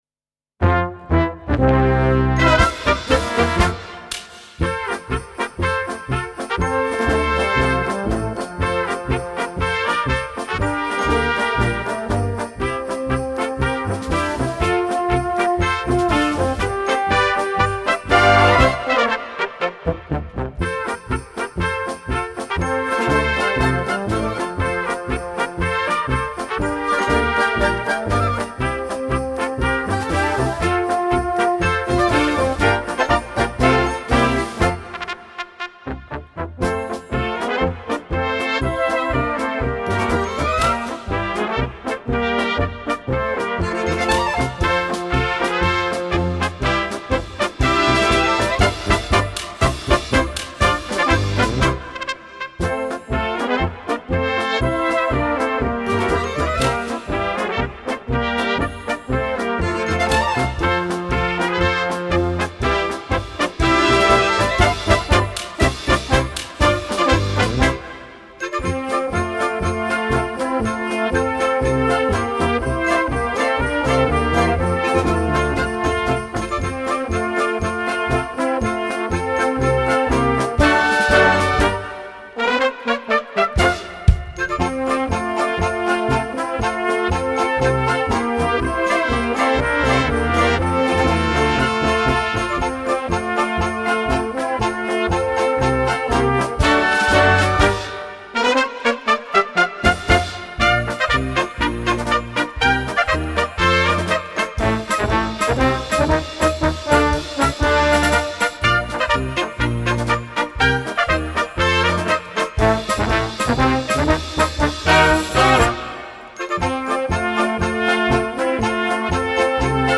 Gattung: Weihnachtsmusik für Blasorchester
Besetzung: Blasorchester
im amüsanten und abwechslungsreichen Mix, der immer wieder